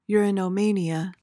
(yoor-uh-no-MAY-nee-uh)